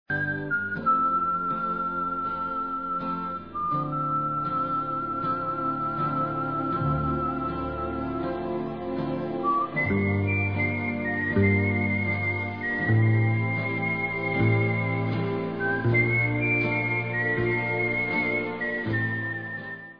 Spaghetti western magic from the meastro